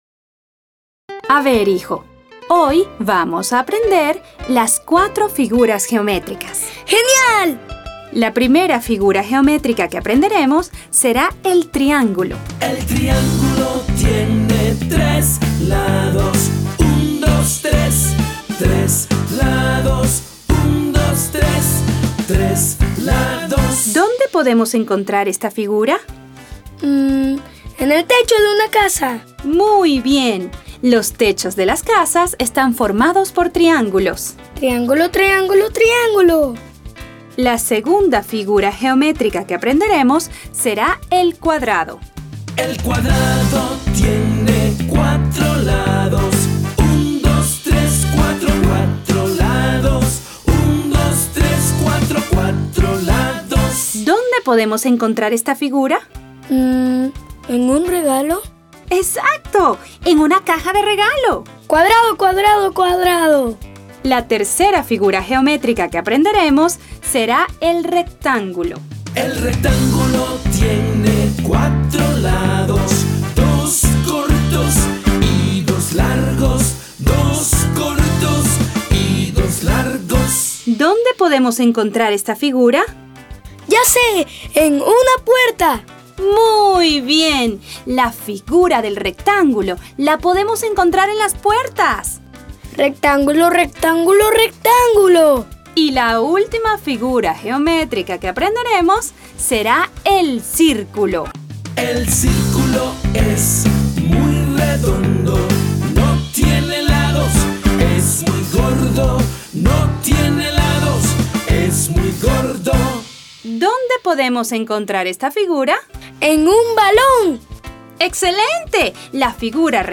Es una cancion con las 4 figuras geométricas que vamos a aprender.